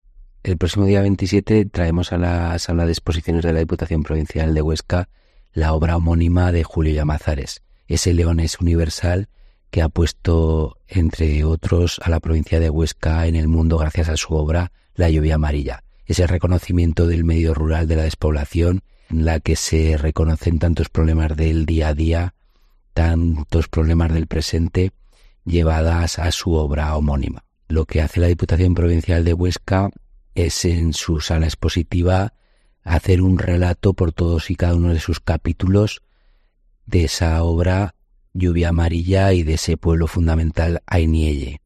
El diputado Carlos Samperiz adelanta los detalles de la próxima exposición de "La lluvia amarilla"